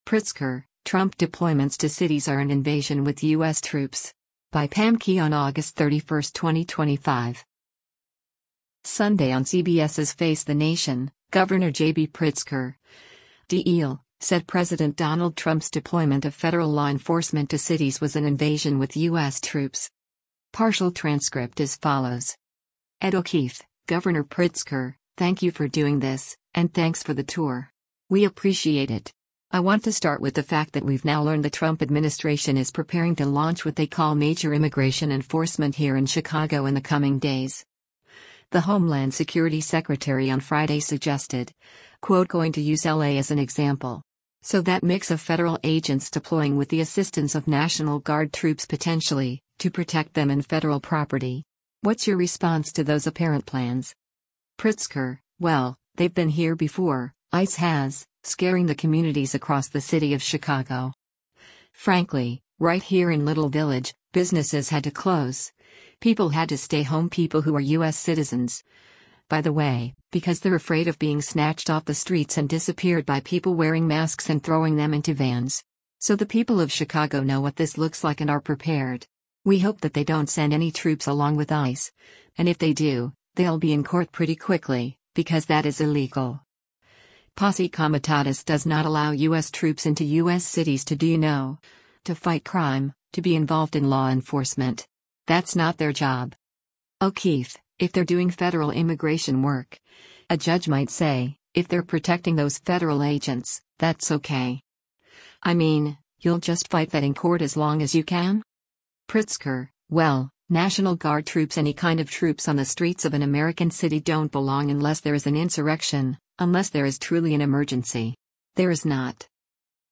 Sunday on CBS’s “Face the Nation,” Gov. J.B. Pritzker (D-IL) said President Donald Trump’s deployment of federal law enforcement to cities was “an invasion with U.S. troops.”